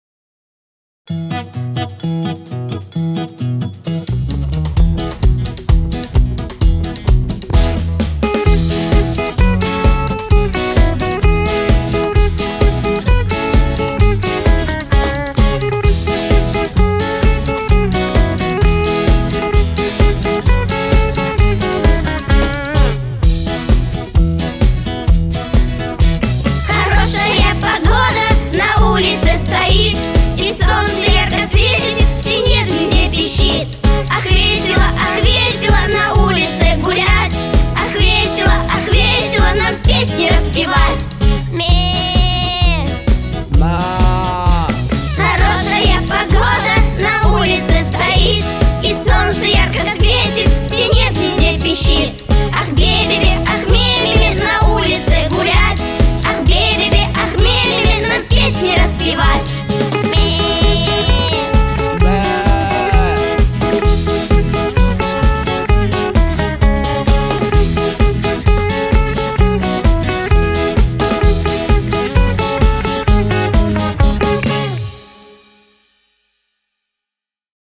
Всё полностью забацано на вариаксе и ПОДе 500-м.
Присутствуют несколько разных аккустических гитар (точнее их эмуляций) ну и остальное.